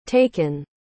Se dice /ˈteɪ.kən/.